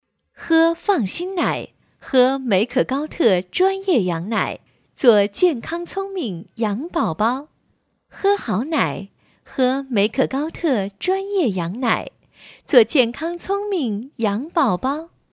女国12温柔舒缓配音-新声库配音网
女国12_广告_食品饮料_美可高特羊奶_亲切.mp3